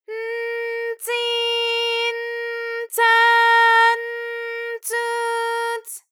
ALYS-DB-001-JPN - First Japanese UTAU vocal library of ALYS.
ts_n_tsi_n_tsa_n_tsu_ts.wav